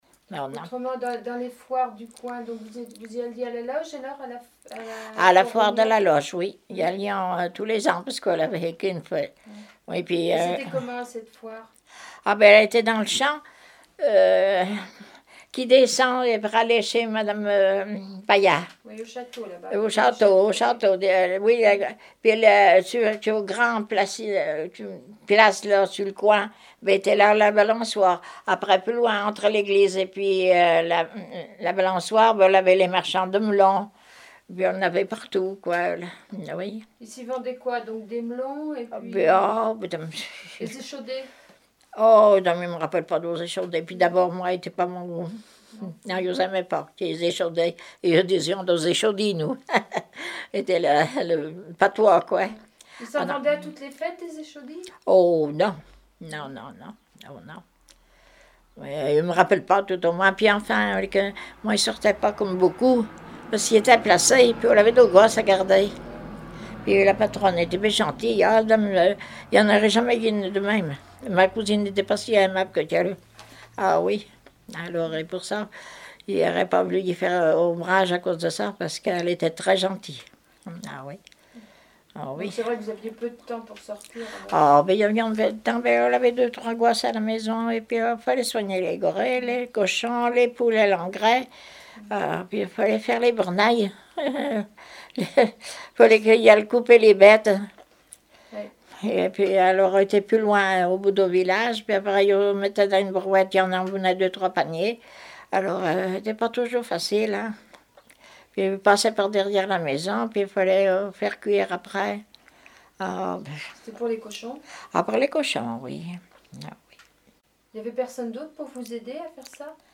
Témoignage sur la vie de l'interviewé(e)